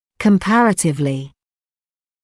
[kəm’pærətɪvlɪ][кэм’пэрэтивли]сравнительно; относительно